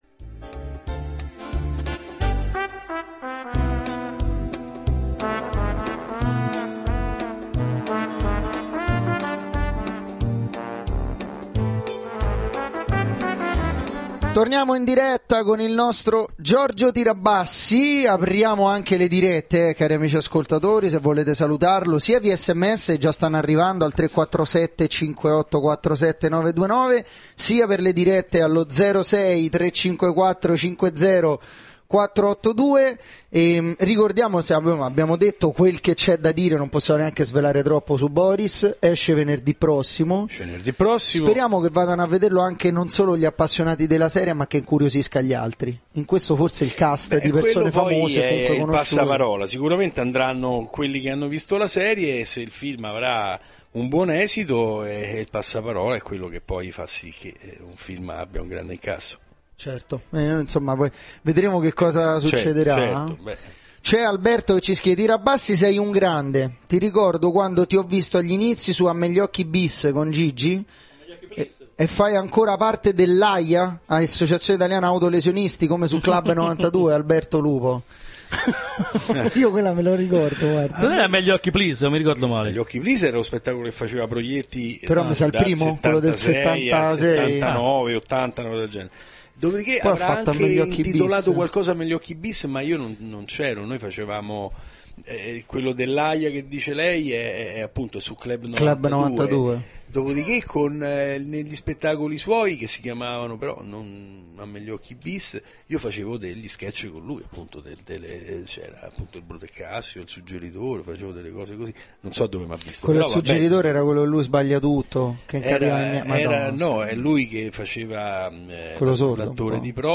Intervento in studio Giorgio Tirabassi del 24/03/2011 - seconda parte